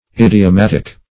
Idiomatic \Id`i*o*mat"ic\, Idiomatical \Id`i*o*mat"ic*al\, a.